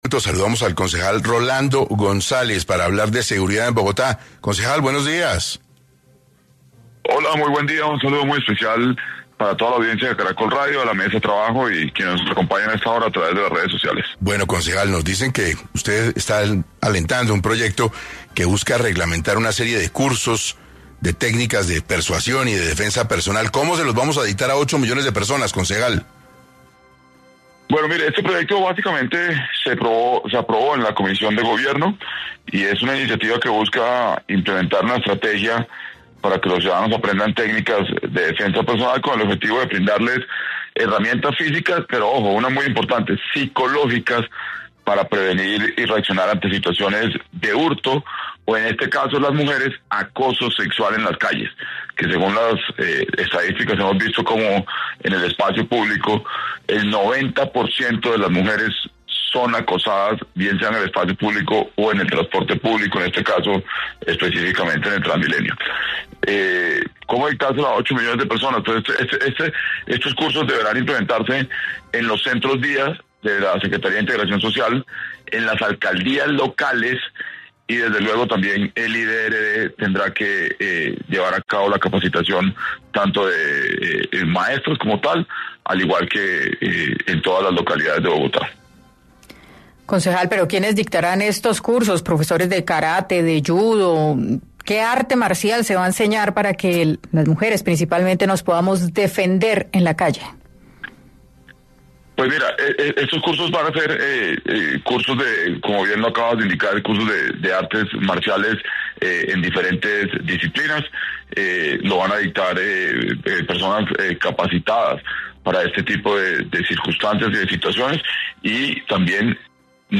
En 6AM habló el concejal Rolando González sobre el nuevo proyecto que avanza en el Consejo para mejorar la seguridad con cursos de defensa personal.